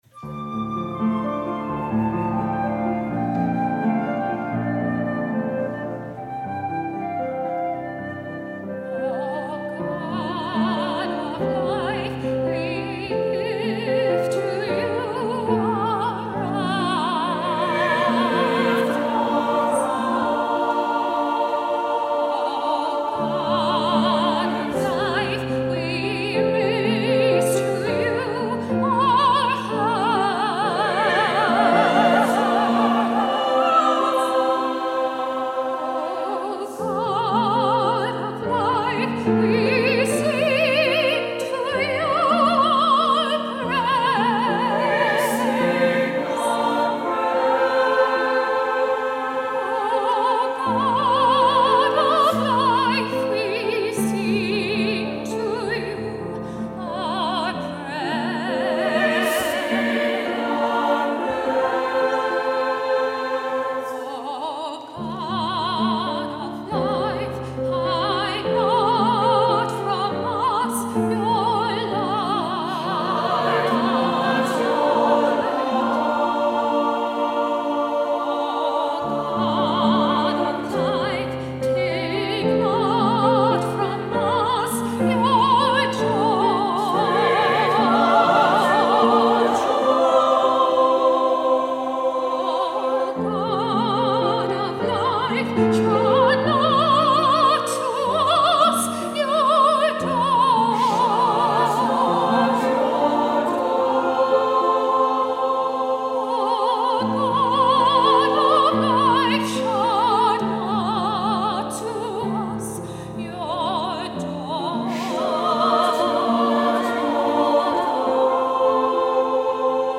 Voicing: "SATB","Solo"